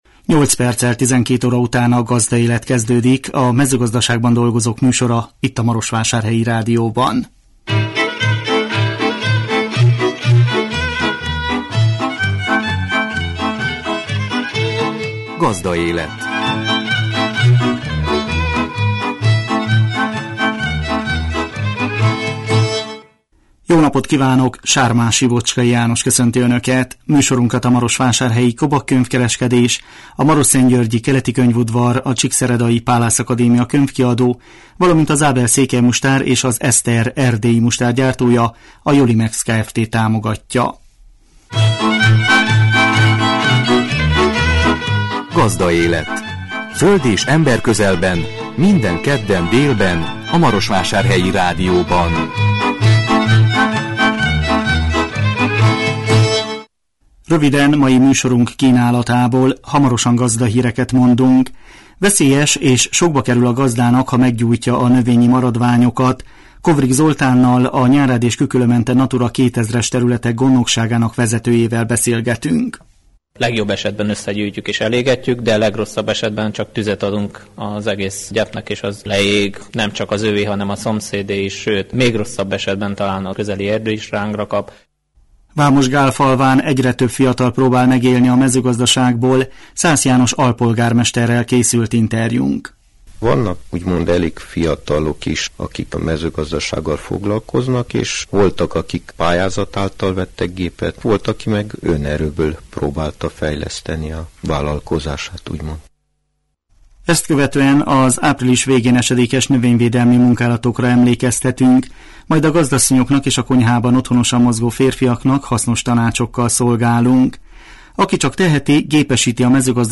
Vámosgálfalván egyre több fiatal próbál megélni a mezőgazdaságból. Szász János alpolgármesterrel készült interjúnk.